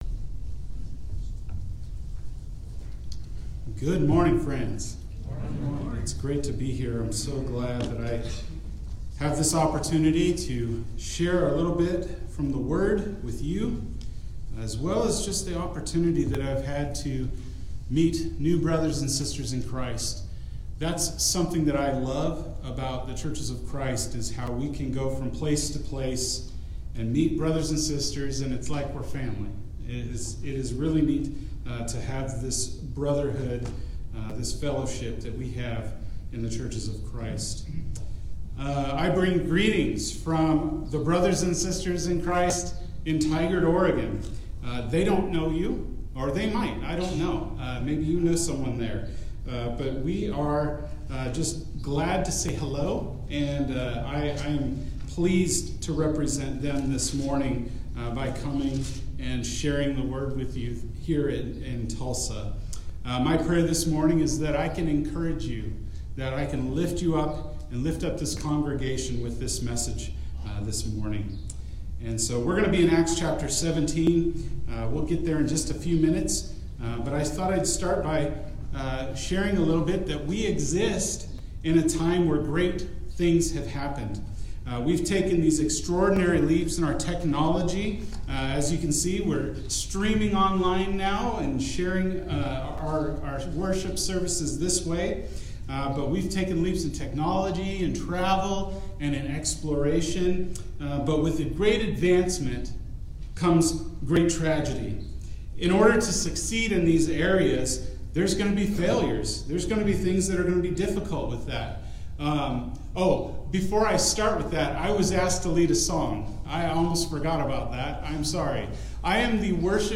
Turn the World Upside Down – Sermon